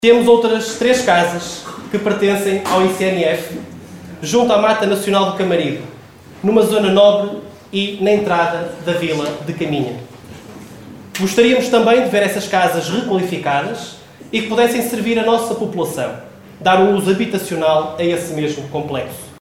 Terminadas as visitas, seguiu-se uma sessão protocolar na Biblioteca Municipal de Caminha, onde as várias entidades procederam às assinaturas dos contratos.